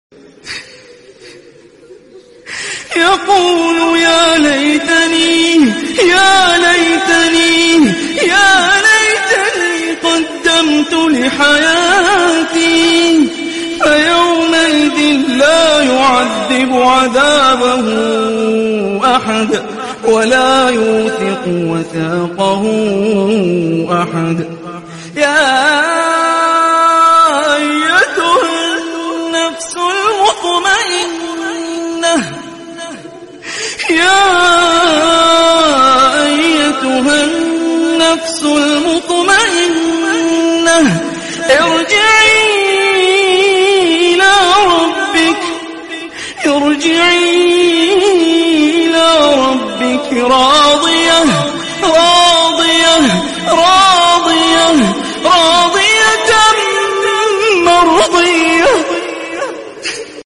Reactions On Emotional Recitation of sound effects free download
Reactions On Emotional Recitation of Holy Quran